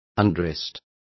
Complete with pronunciation of the translation of undressed.